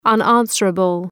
Προφορά
{ʌn’ænsərəbəl}
unanswerable.mp3